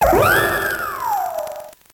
Cri de Farfaduvet dans Pokémon Noir et Blanc.